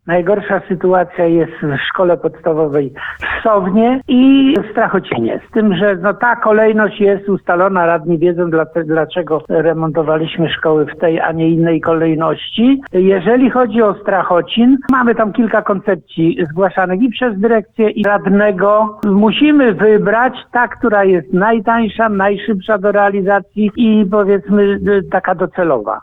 – wyjaśnia wójt Gminy Stargard, Kazimierz Szarżanowicz. Nad utworzeniem trzech dodatkowych klas w budynku szkoły trwają już prace.